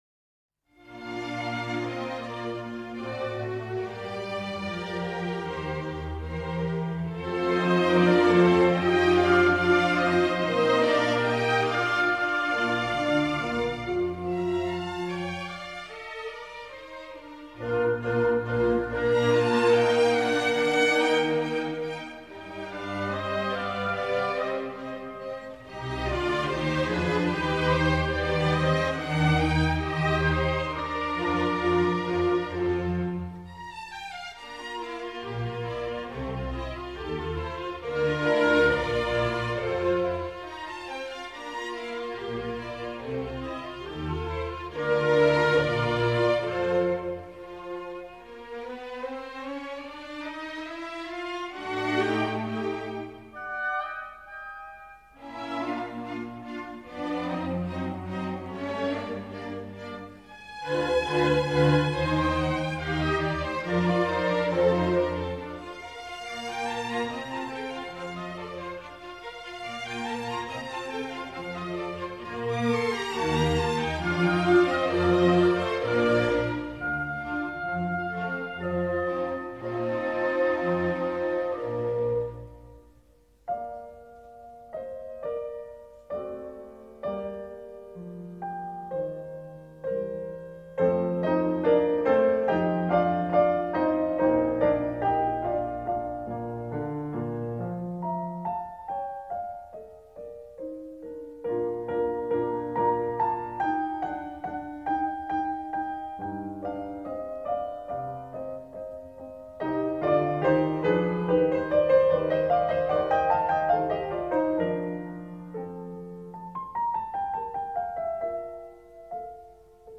Mozart, Concerto In F For 3 Pianos, K 242 – 2. Adagio